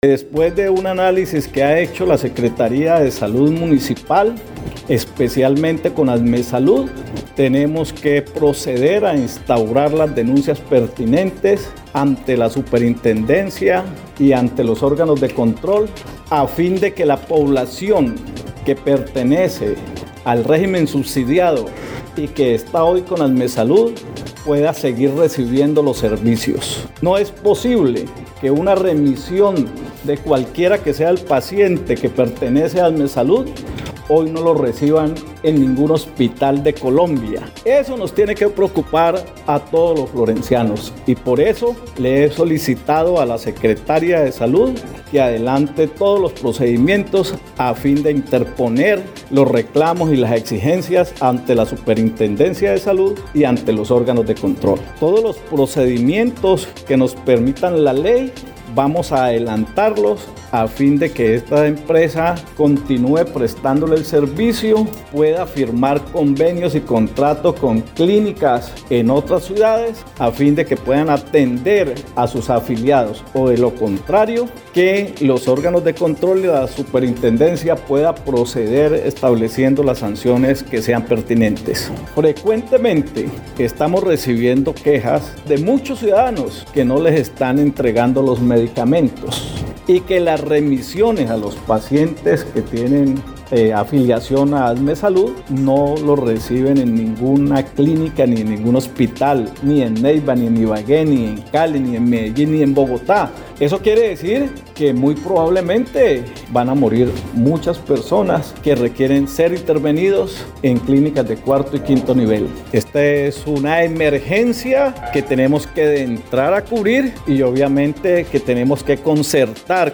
Luis Antonio Ruiz Ciceri, alcalde de la ciudad de Florencia, explicó que lo anterior pone en riesgo la salud de aquellos usuarios que requieren de atención médica especializada, misma es que es dilatada en sus remisiones a centros asistenciales de niveles complejos al interior del país.
01_ALCALDE_LUIS_ANTONIO_RUIZ_ASMET.mp3